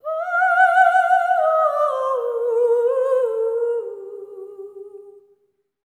LEGATO 03 -L.wav